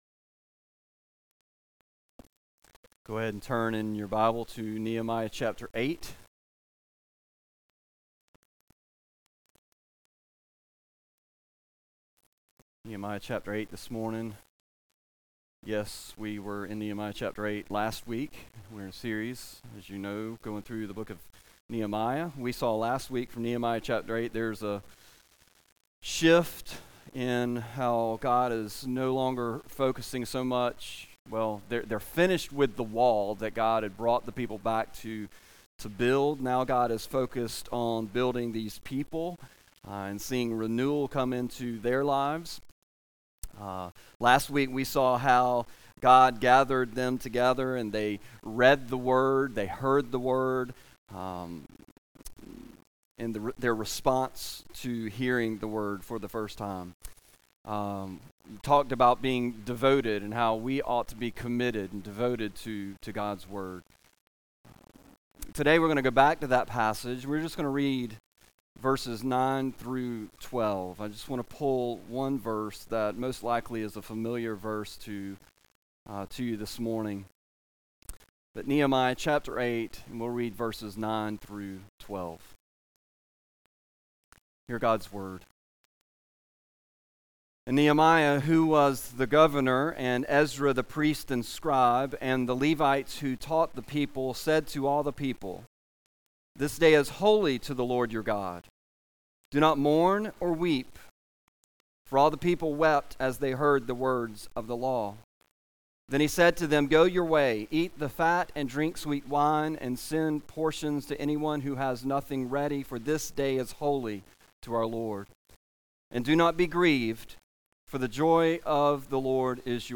A message from the series "Nehemiah."